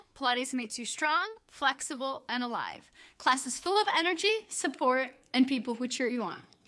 Cleaned Sample: